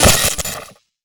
poly_explosion_lightning.wav